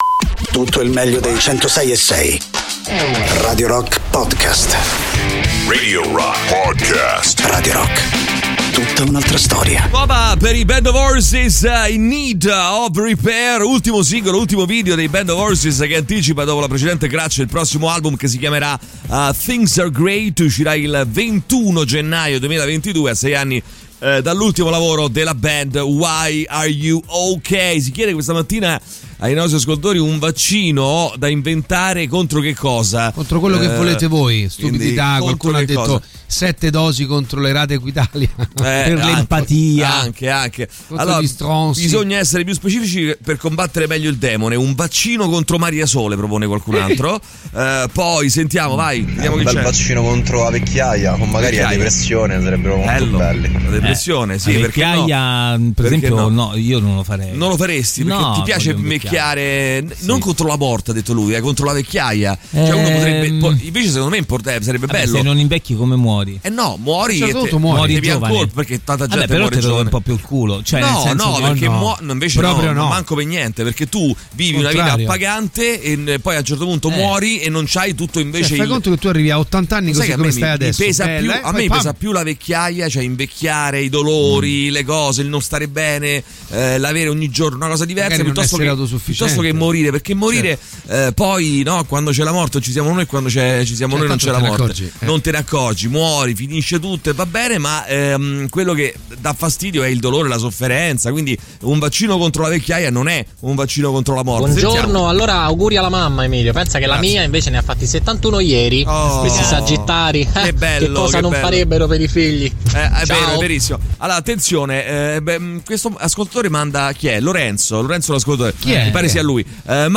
in diretta dal lunedì al venerdì dalle 6 alle 10 sui 106.6 di Radio Rock.